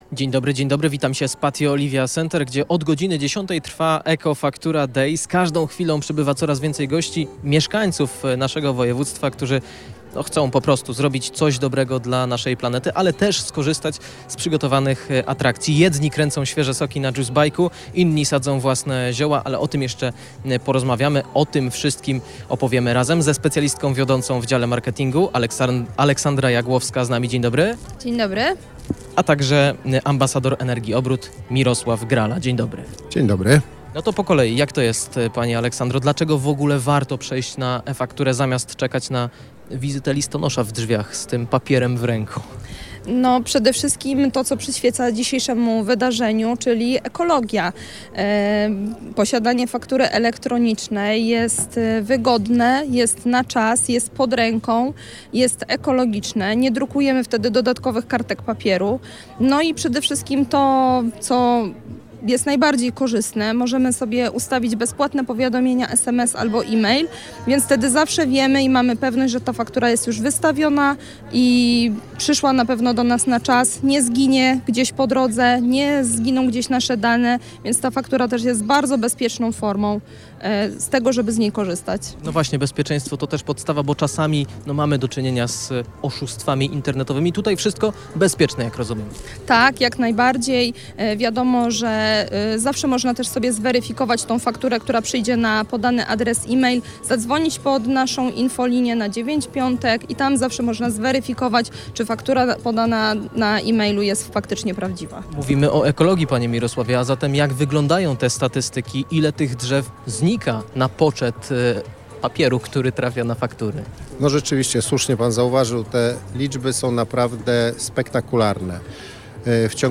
rozmowka-energa.mp3